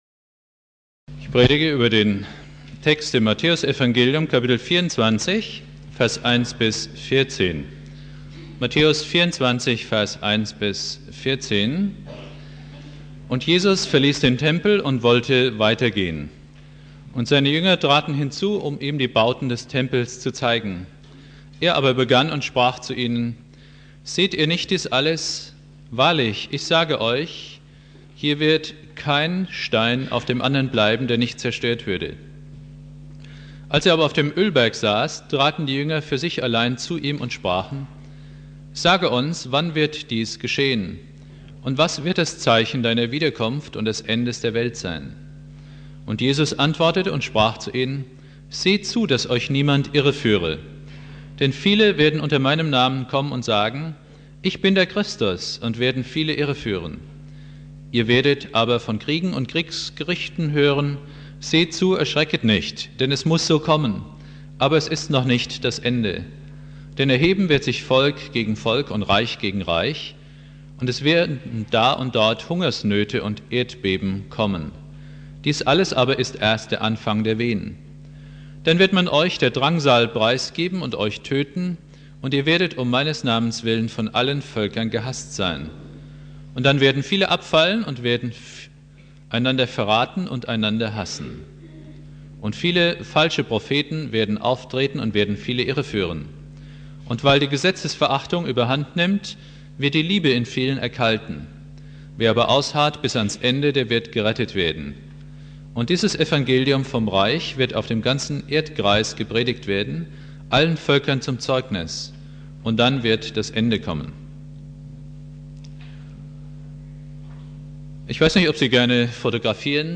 Predigt
1.Advent